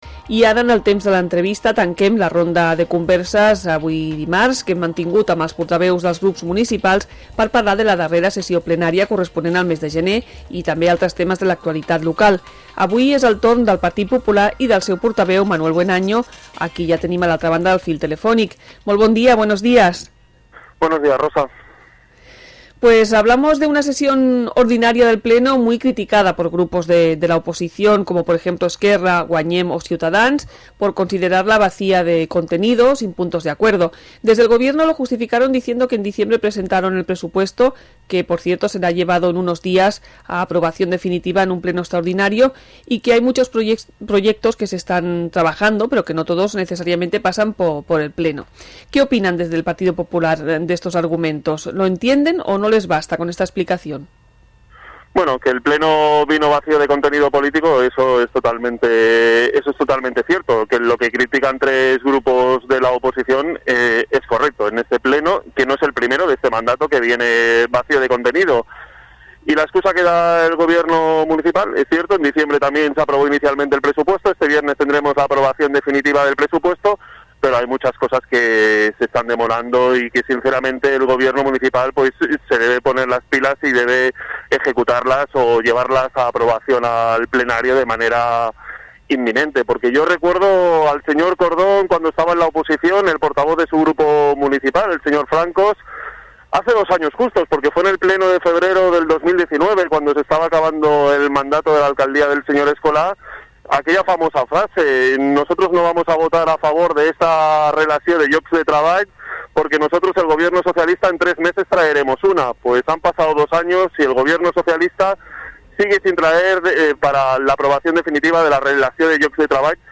Entrevista Manuel Buenaño